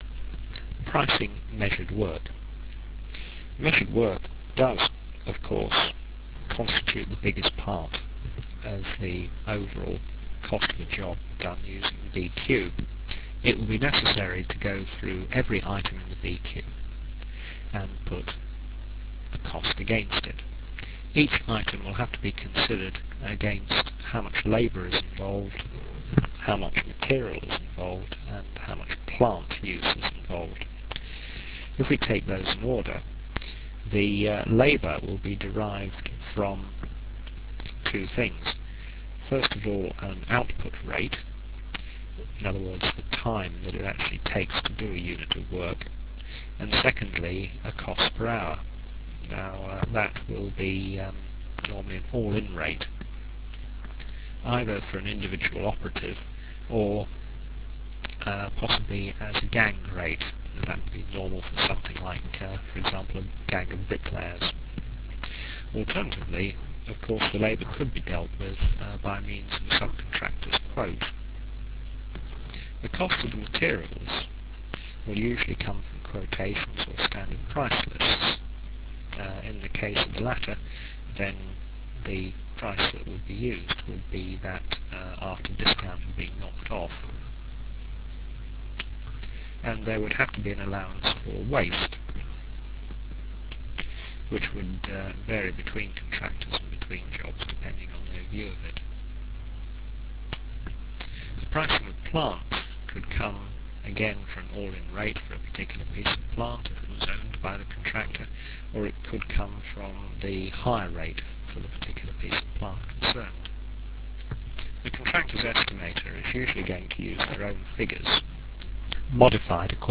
The sound quality is adequate but low fi.
Enjoy your lectures.........